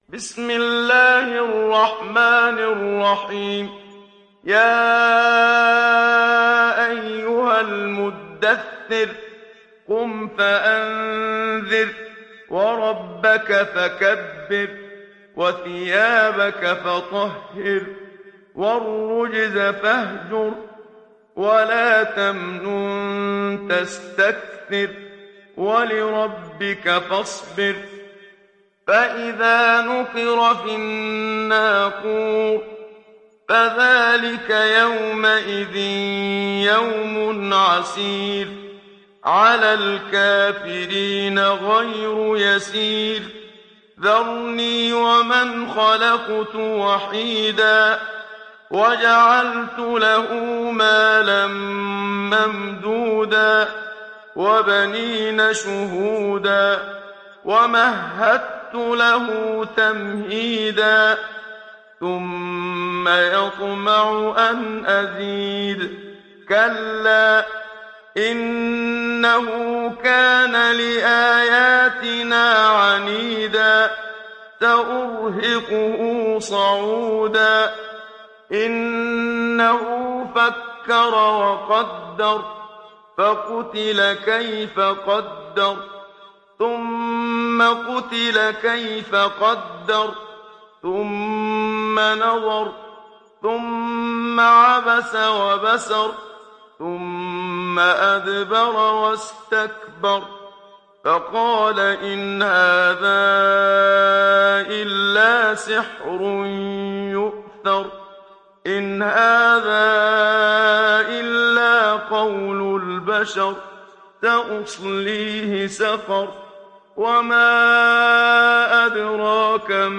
Müdahhir Suresi mp3 İndir Muhammad Siddiq Minshawi (Riwayat Hafs)
Hafs an Asim